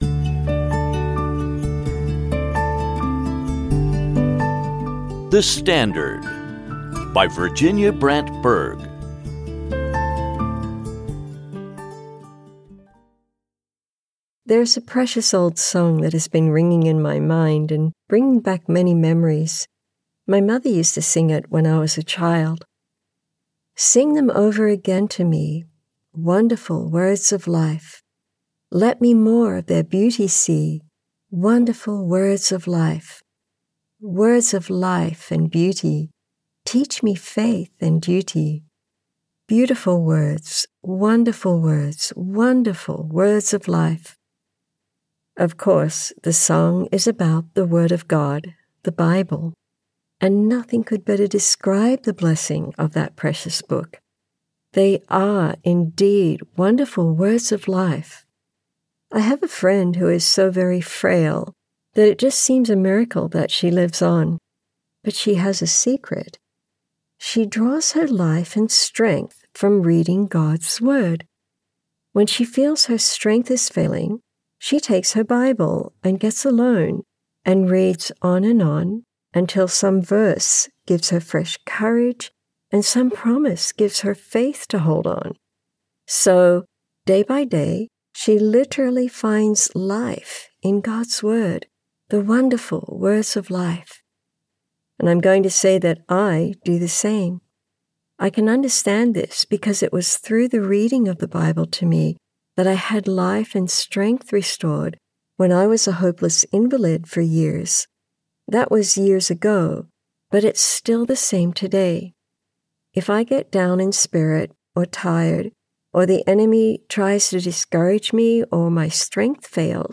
From a transcript of a Meditation Moments broadcast, adapted.